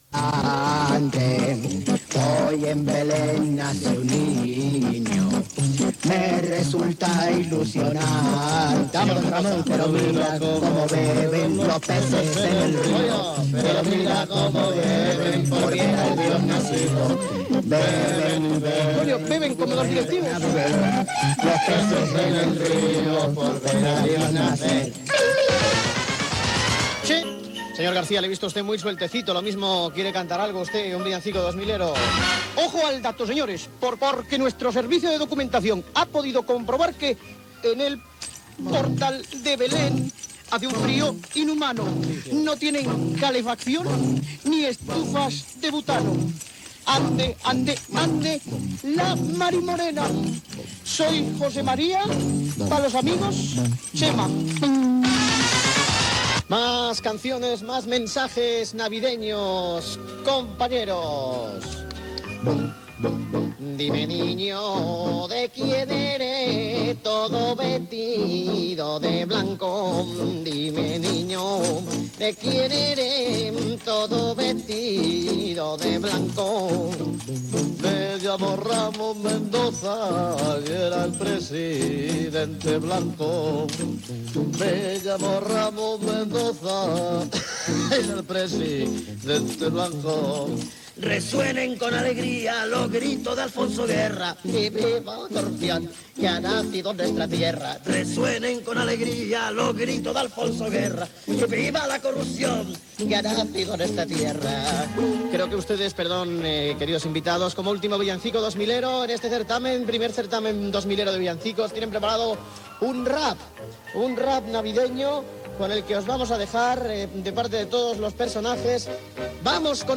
Concurs de nadales cantades per les imitacions de José María García, Alfonso Guerra, Narcís Serra, Javier Solana i altres persones populars de l'època
Entreteniment